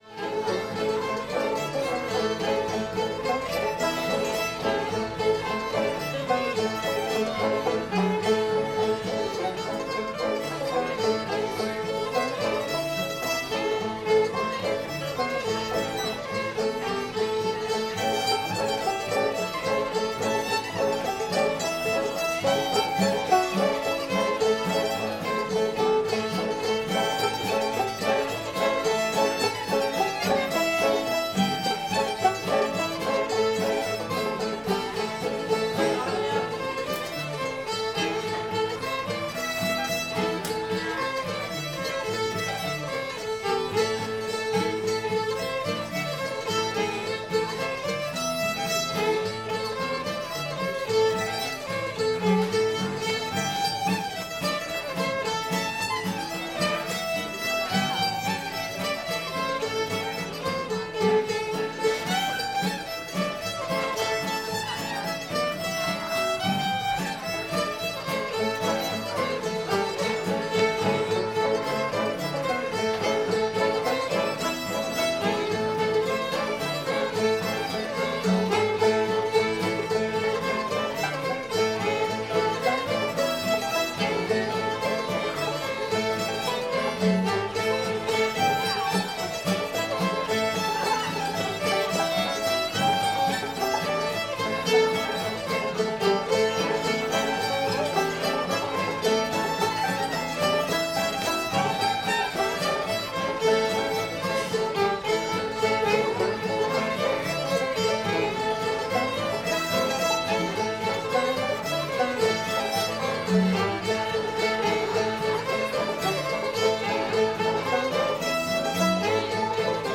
lonesome john [A modal]